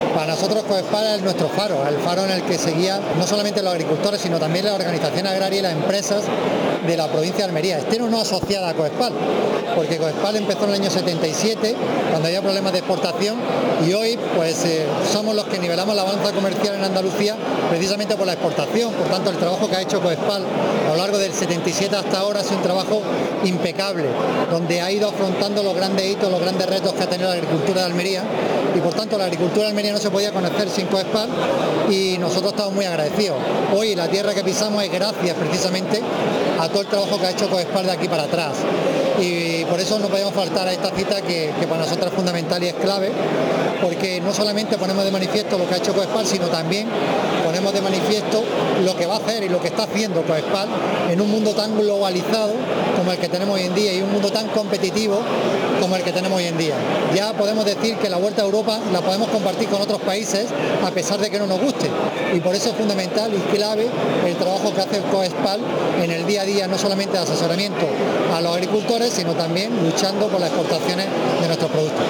Javier A. García ha participado en la inauguración de la 48 Asamblea de Coexphal donde ha ensalzado el trabajo de la organización en defensa y la unión del sector